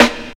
64 SNARE 3.wav